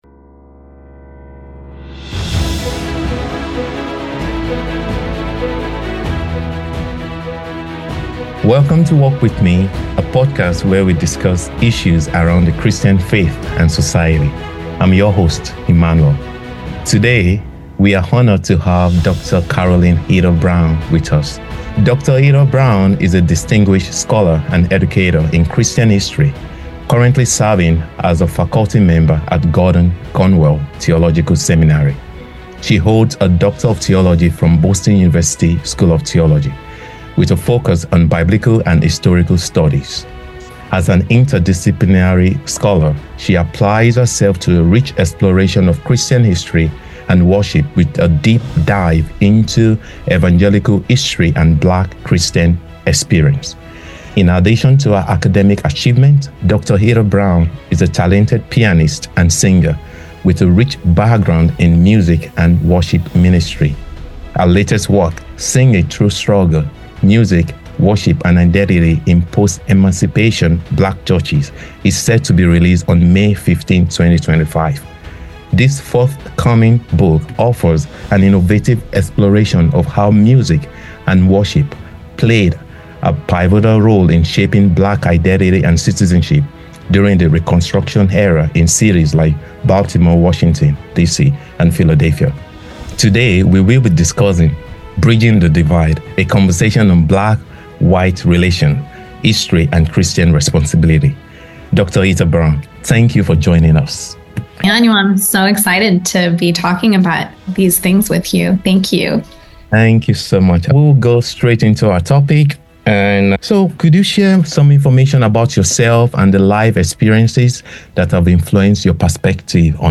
Part 1: An Attempt on Bridging the Divide: A Conversation on Black-White Relations, History, and Christian Responsibility